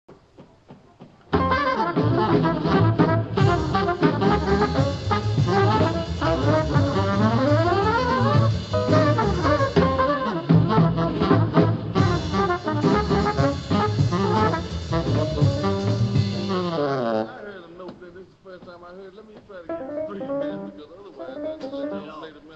STUDIO RECORDING SESSIONS OF NEW YORK CITY 03/04/1958